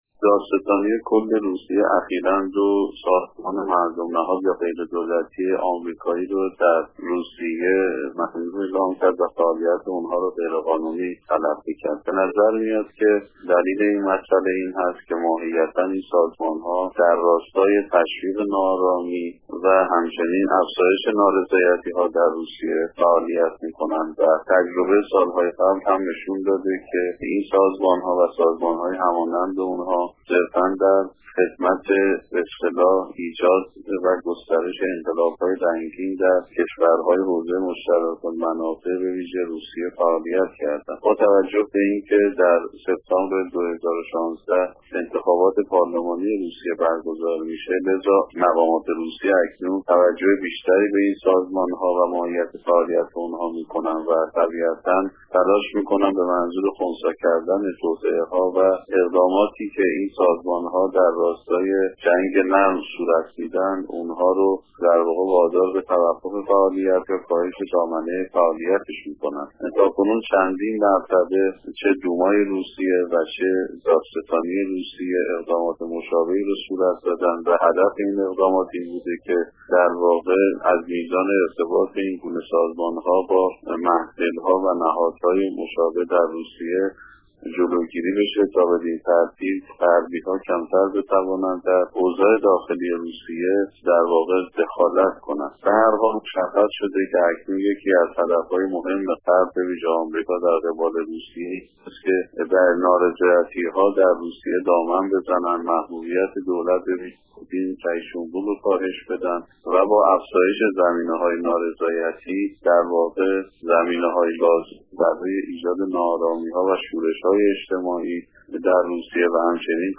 گفت و گو با خبرنگار رادیو دری